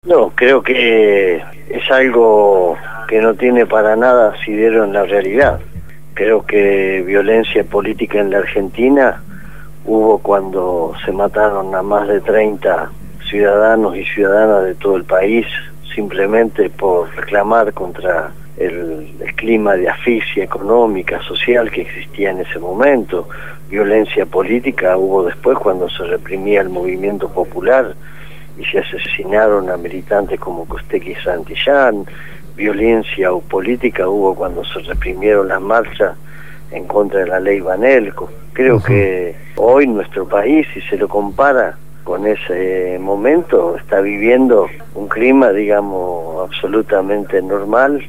El Secretario General de la CTA fue entrevistado